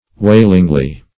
\Wail"ing*ly\